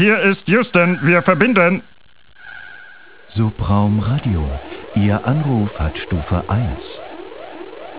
Music-On-Hold:
die Sci-Fi-Variante